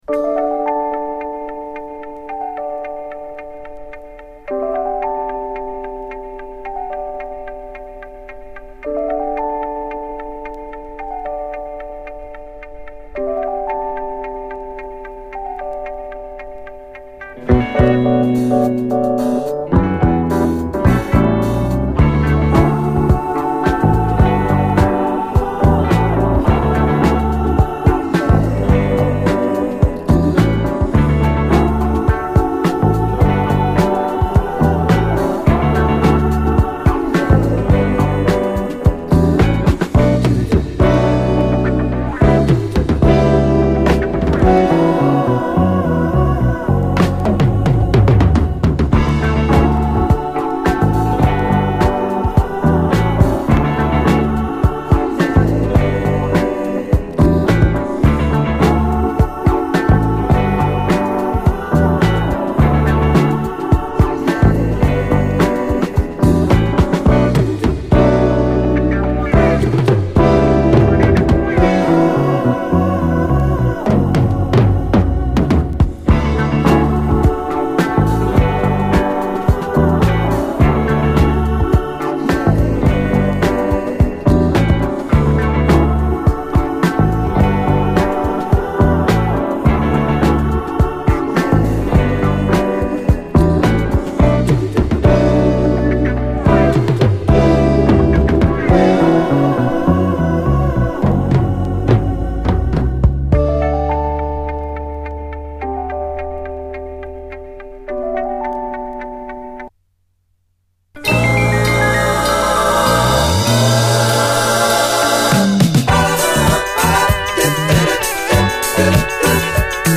UKのファンキー・レゲエ・バンド！
完全にジャマイカン・ファンク状態、後半の長いブレイクも驚きの、重量級ジャマイカン・ブラス・ファンク